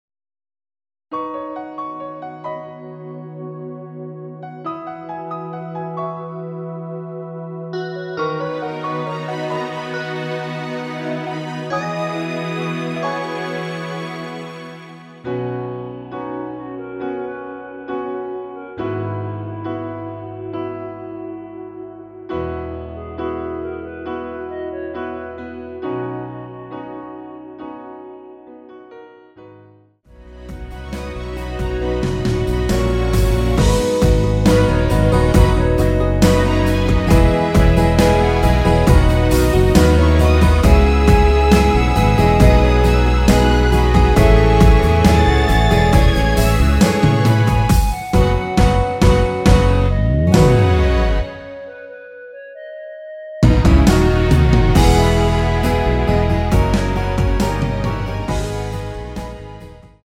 원키에서(-7)내린 멜로디 포함된 MR입니다.(미리듣기 참조)
음정과 박자 맞추기가 쉬워서 노래방 처럼 노래 부분에 가이드 멜로디가 포함된걸
앞부분30초, 뒷부분30초씩 편집해서 올려 드리고 있습니다.